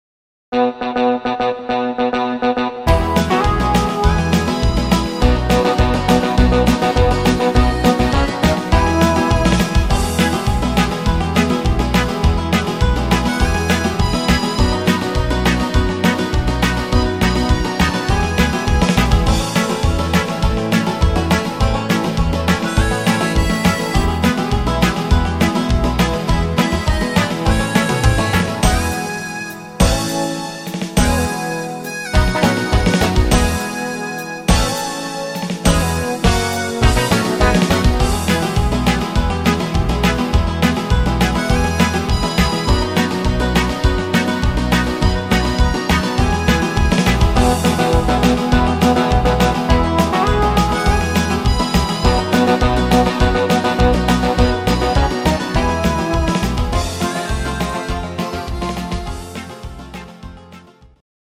Rhythmus  Country
Art  Deutsch, Oldies, Schlager 60er